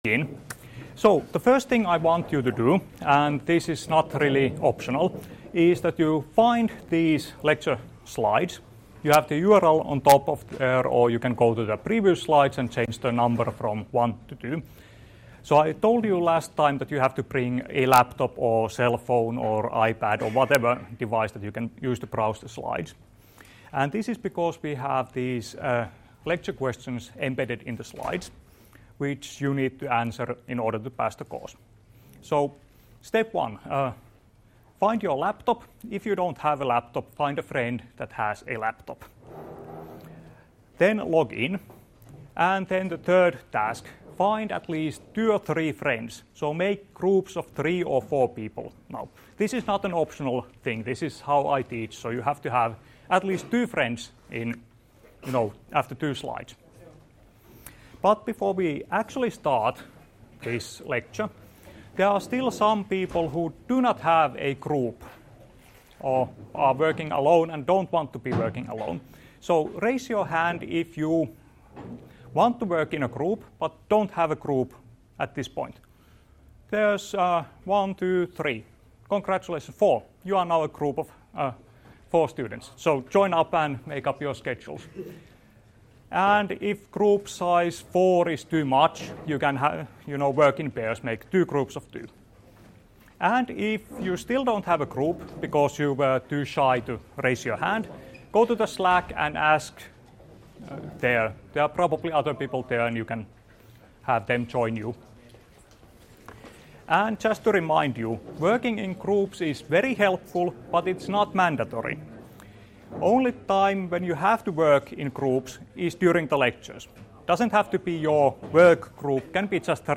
Luento 16.1.2017 — Moniviestin